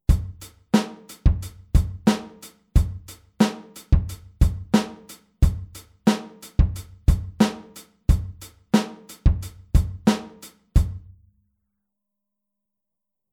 Hören wir uns zunächst den 8tel-Groove doch mal an.
Falls ja, erinnerst du dich bestimmt an die 8tel-Beat Variante, bei der der Kick auf den beiden unds zwischen 2 und 4 kommt.
Groove02-8tel.mp3